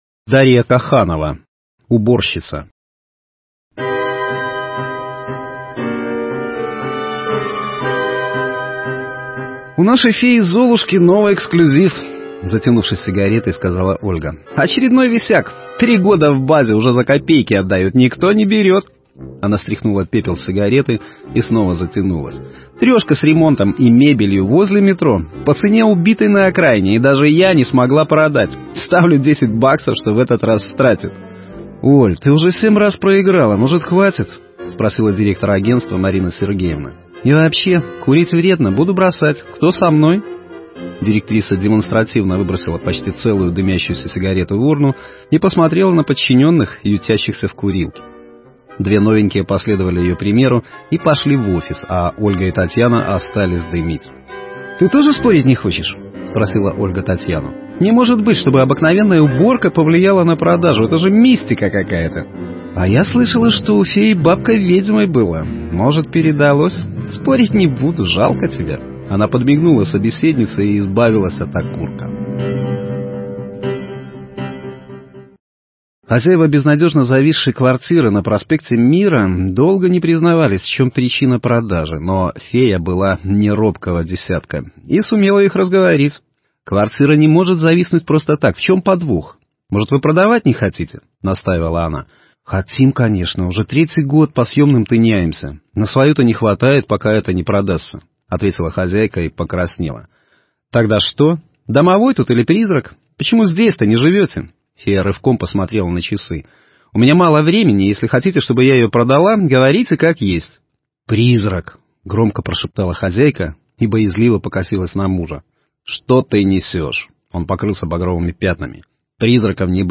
Аудио-Рассказы